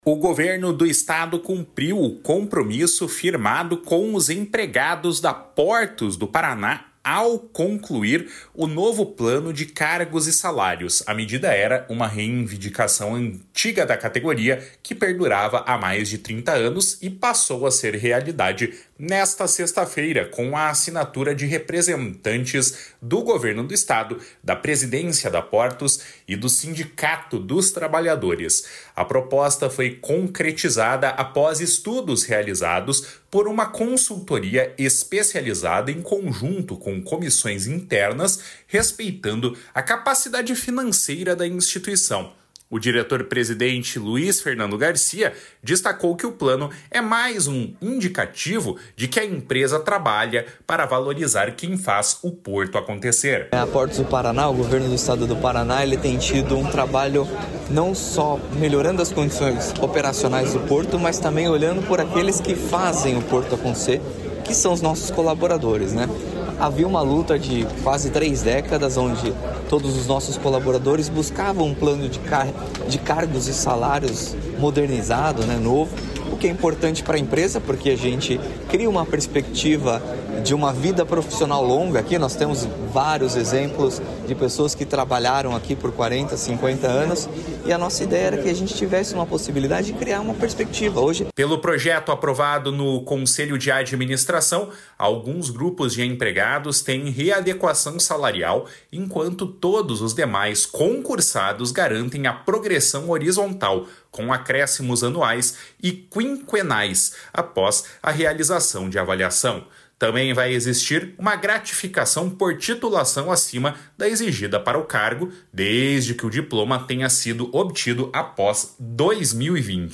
O diretor-presidente Luiz Fernando Garcia, destacou que o Plano é mais um indicativo de que a empresa trabalha para valorizar quem faz o Porto acontecer.
Para o prefeito de Paranaguá, Adriano Ramos, o Plano ajuda a economia local.